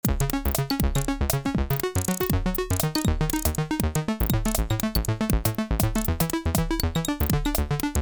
Midi KB - Ableton - MH - Moog Voyager